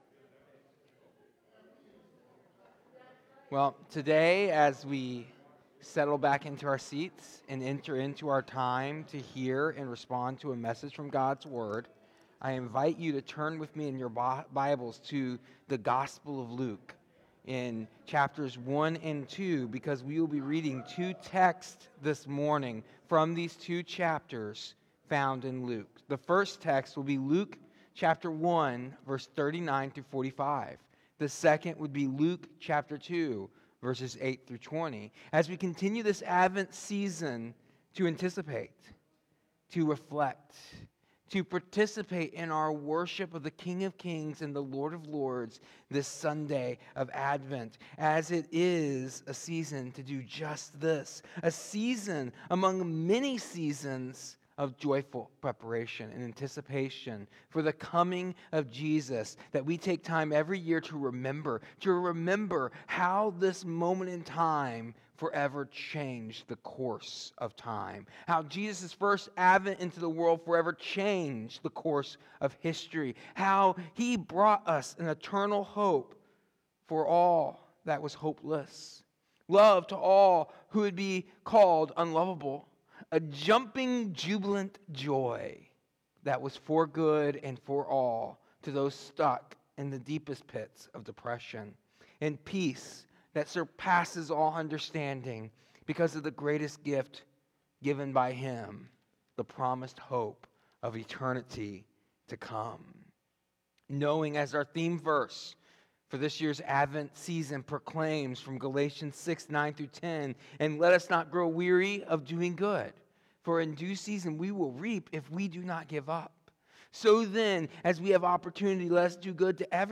This sermon reflects on the true nature of joy revealed in Jesus’ first coming, drawing from Luke 1:39-45 and Luke 2:8-20. It contrasts the fleeting, circumstance-based happiness of our world with the lasting, jubilant joy that comes from Christ alone.